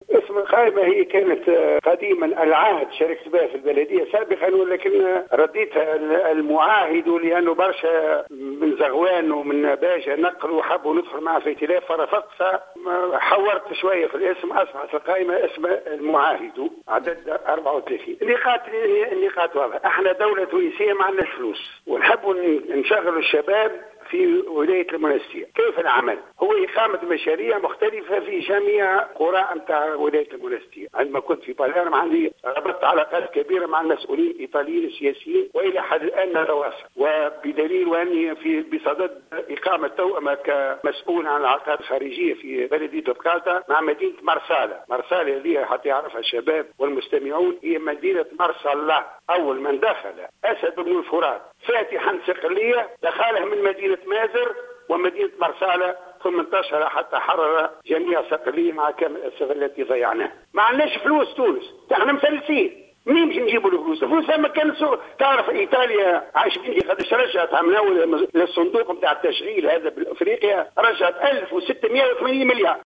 تصريح للجوهرة "اف ام"